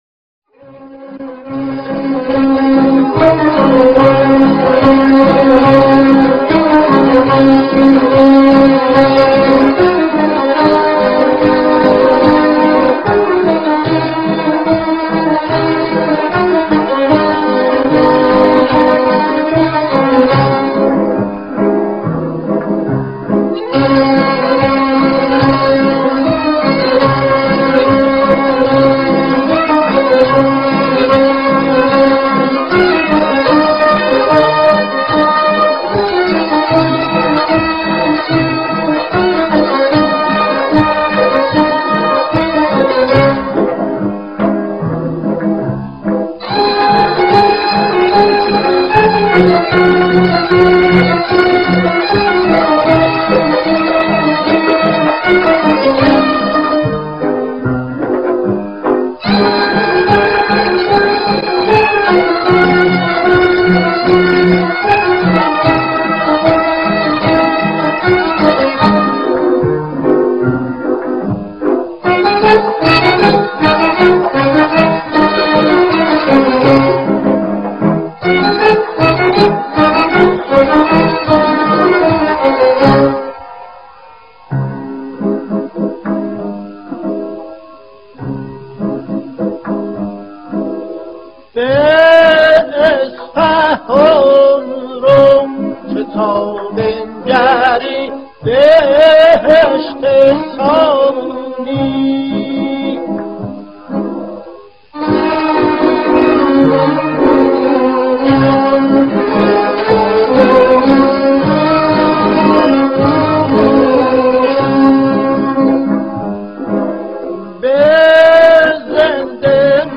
تار
نی
ویولن
تنبک